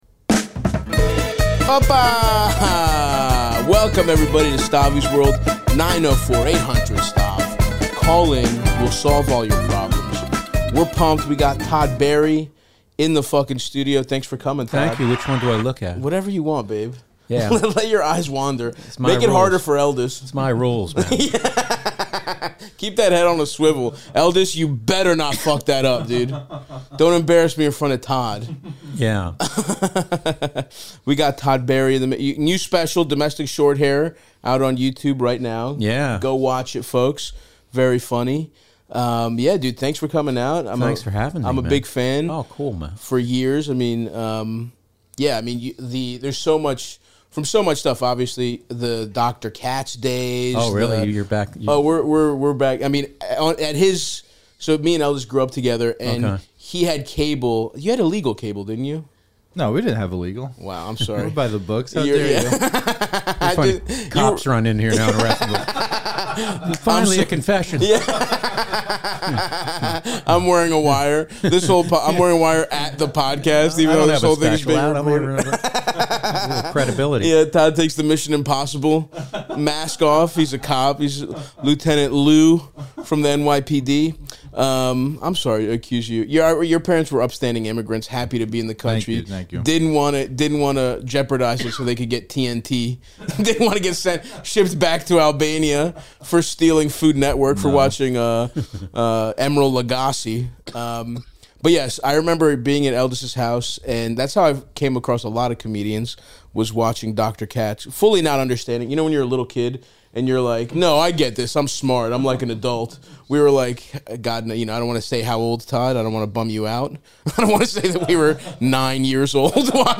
Todd Barry joins the pod to discuss his new special 'Domestic Short Hair,' meeting Joe Jonas, playing as a drummer, how he got his role in Darren Aronofsky's 'The Wrestler', and much more. Stav and Todd help callers including a woman whose creepy coworker keeps inviting her to DJ events at his house, and a small-time bookie stealing from his degenerate gambler coworker.